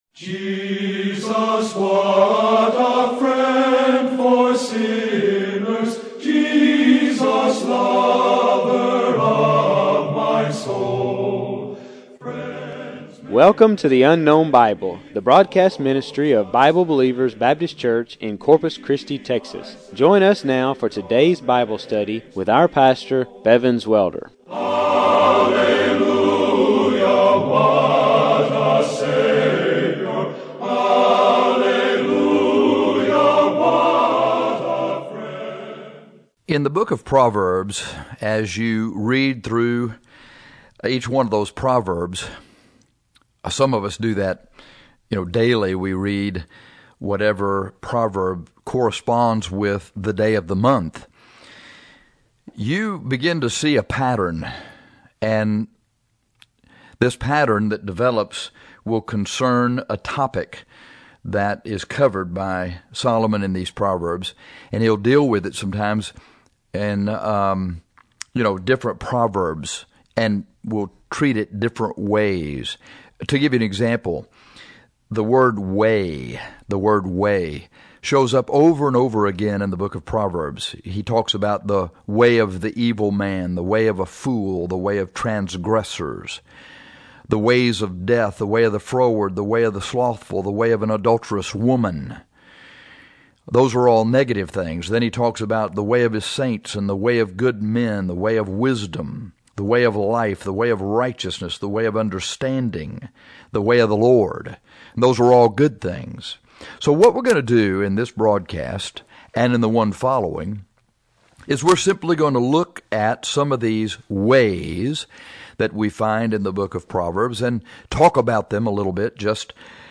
In this broadcast, we are going to cover the negative ways.